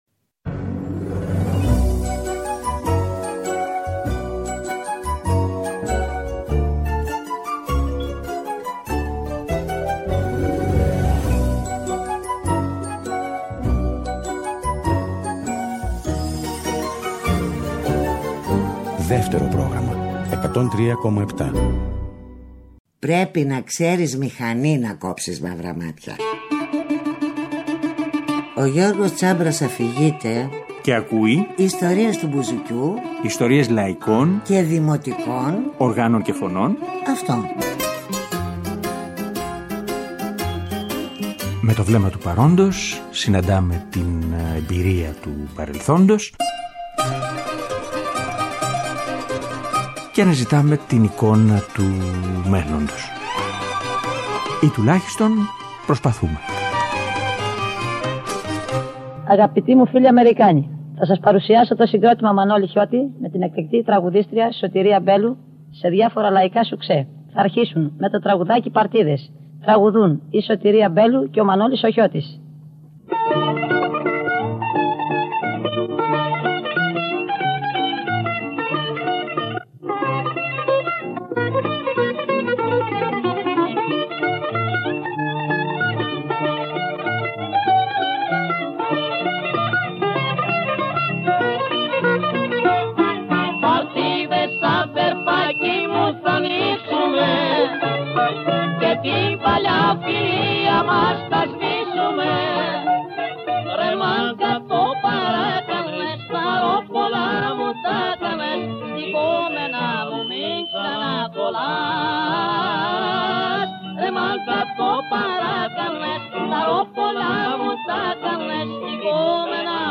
Και όλοι μαζί παίζουν και τραγουδούν
ένα ρουμελιώτικο τσάμικο